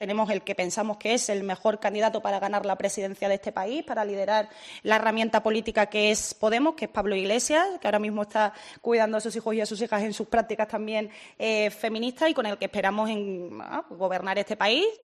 Sin esa máxima no se puede explicar la comparecencia de Noelia Vera, diputada y portavoz adjunta del partido comunista, cuando explicaba que Pablo Iglesias es “el mejor candidato”.